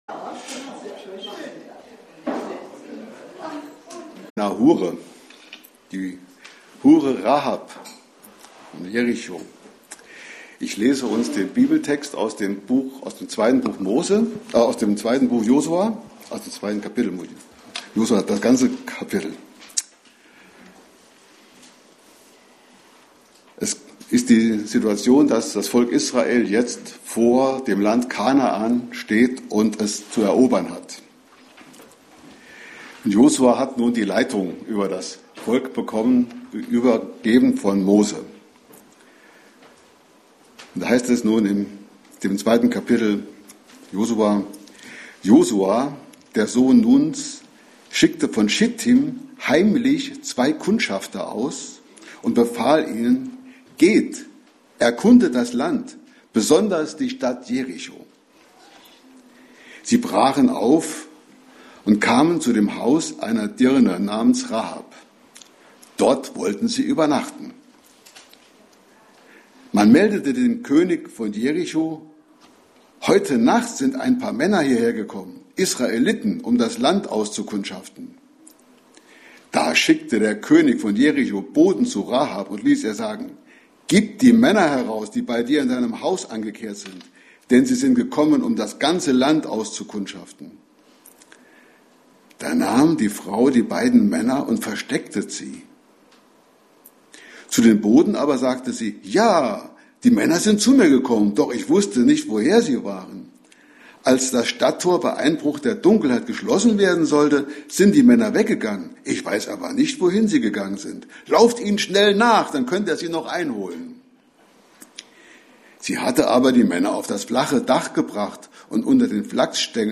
Passage: Daniel 6, 1-28 Dienstart: Predigt